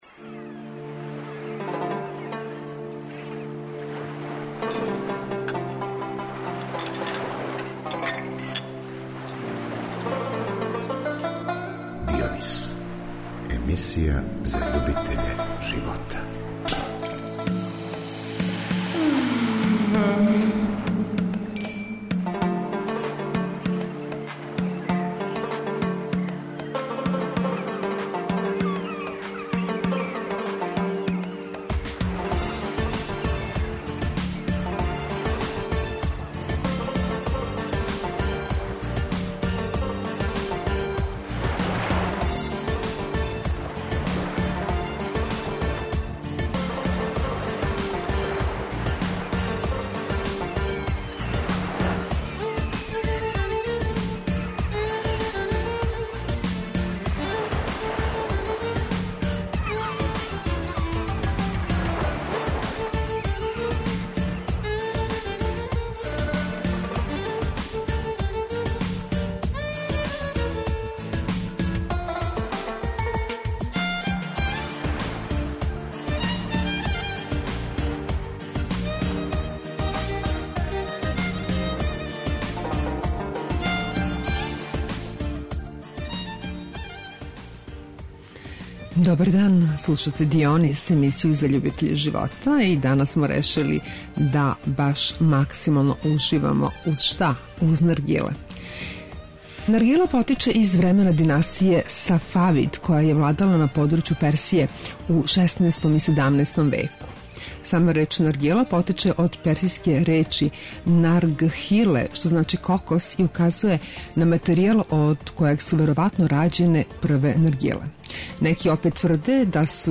То уживање потражили смо и ми у једном од београдских наргила барова....